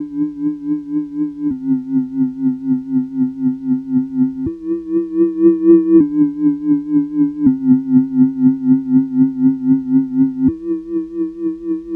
Track 16 - Flying Saucer FX.wav